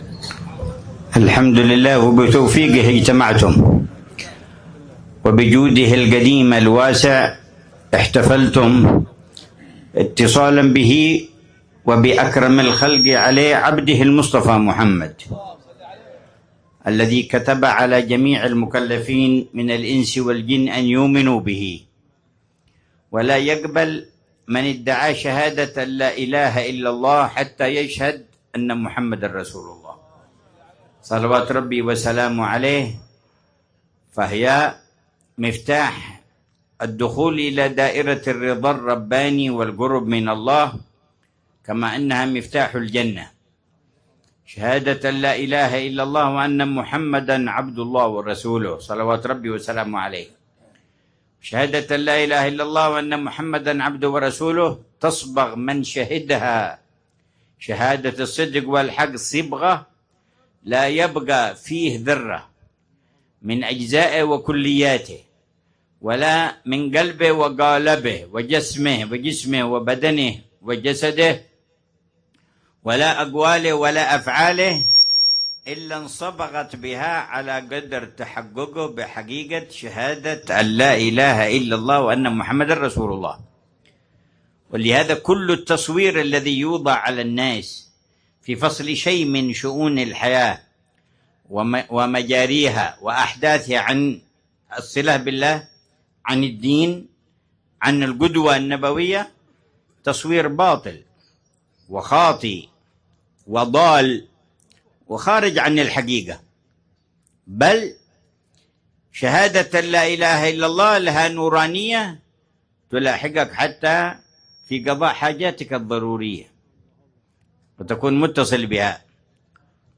محاضرة العلامة الحبيب عمر بن محمد بن حفيظ في المولد السنوي في سوق تريم، ليلة الأربعاء 18 ربيع الأول 1447هـ بعنوان :